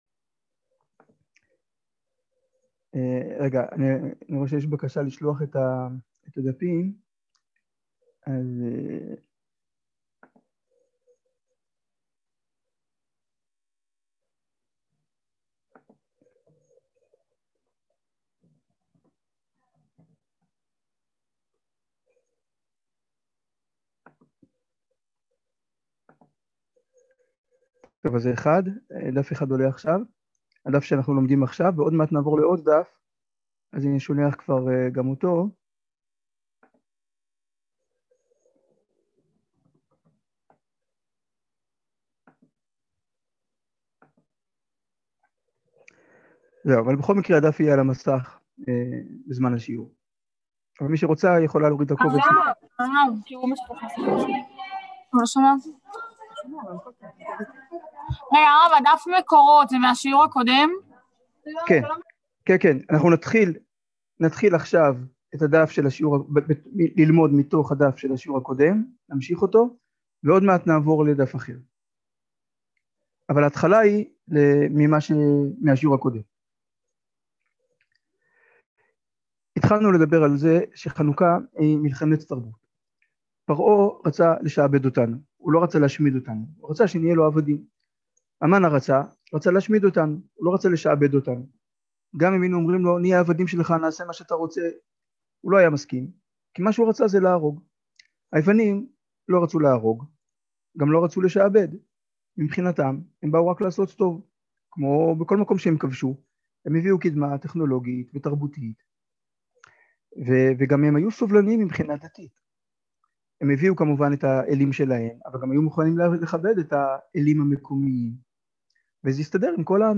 איך מוצאים את פך השמן? | יום עיון תשפ"א | מדרשת בינת